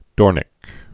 (dôrnĭk)